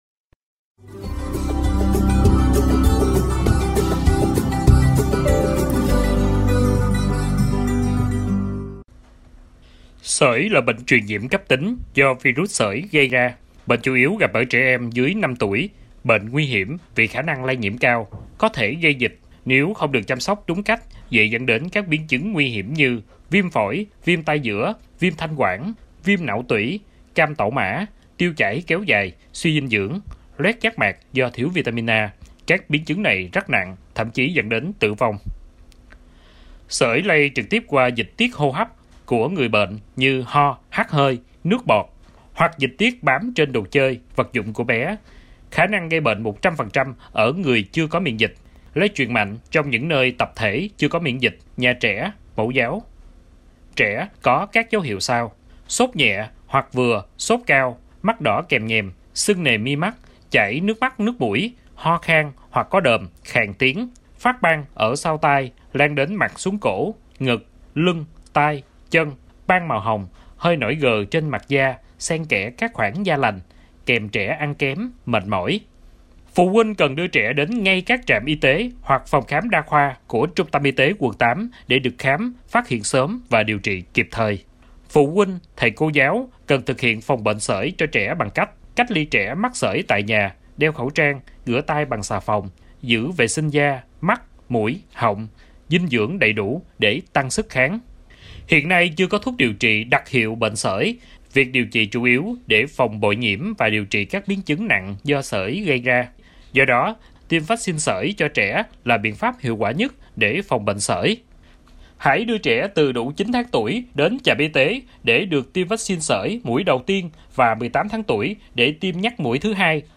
Bài phát thanh tuyên truyền phòng, chống dịch Sởi